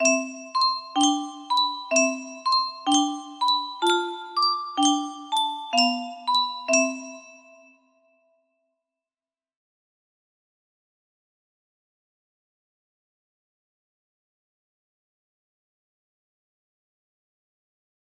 Bubble Bath Waltz music box melody